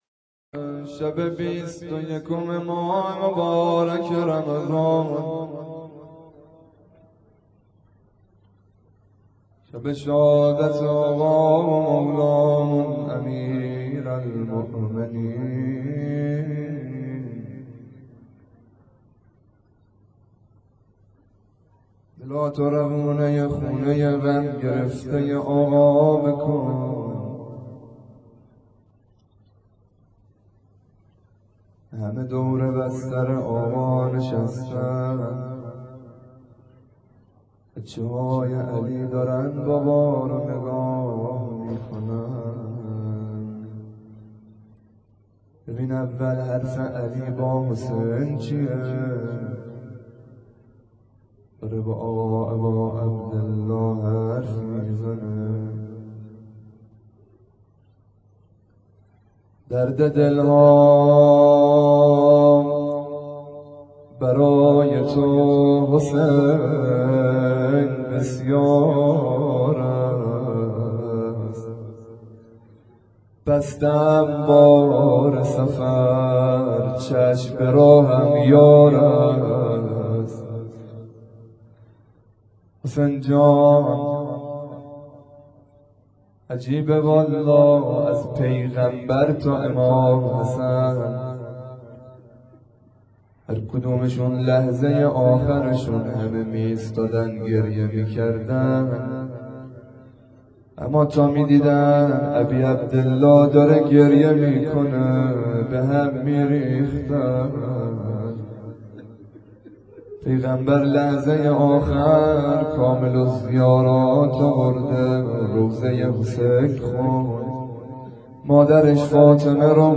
روضه امام علی (ع) _ مسجد بیت الله